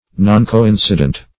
Noncoincident \Non`co*in"ci*dent\, a.